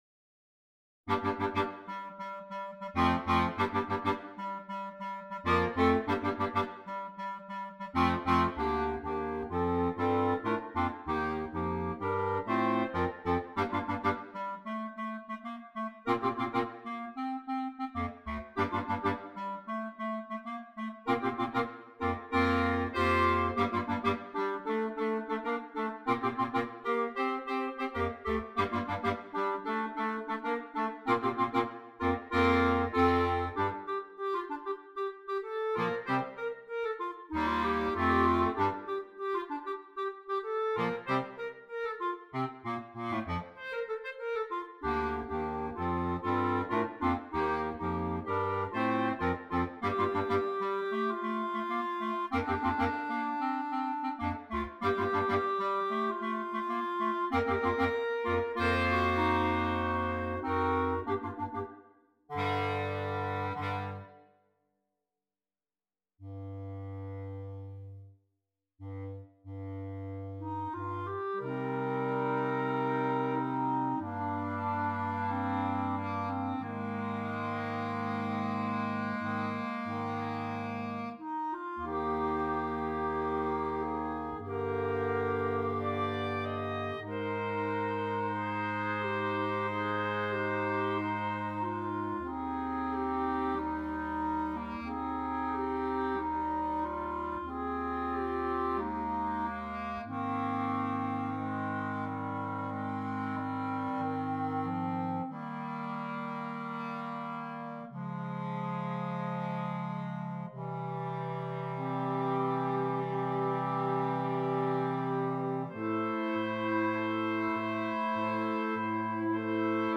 Gattung: Für 4 Klarinetten und Bassklarinette